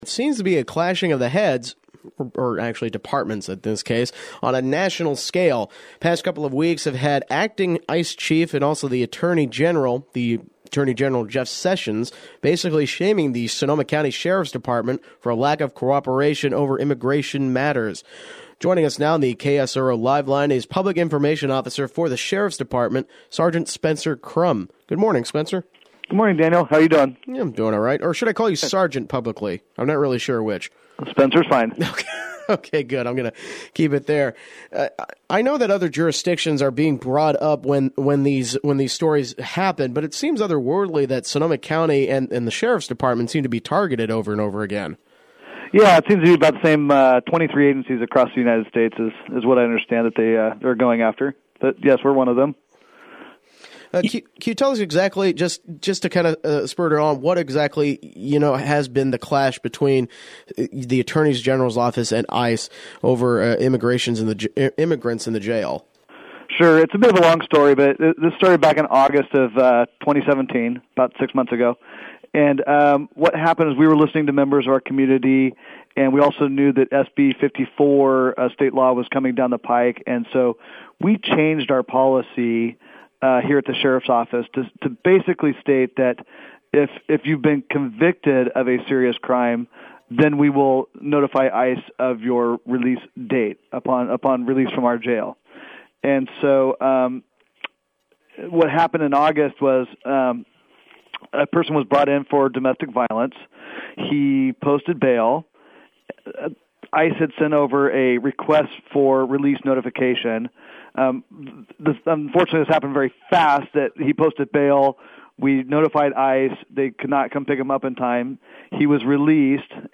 Interview: Sonoma County Targeted for Immigration Policies Again